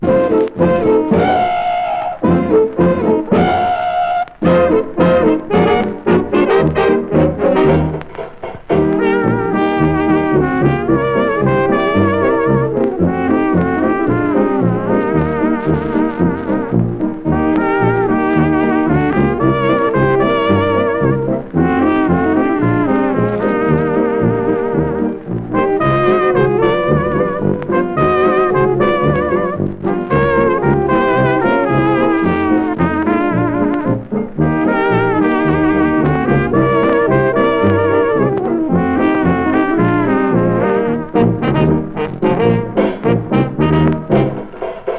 音の抜き差し、機知に富んだアレンジが素晴らしい。テンション高め、興奮必至の演奏は圧巻です。
Jazz　Canada　12inchレコード　33rpm　Mono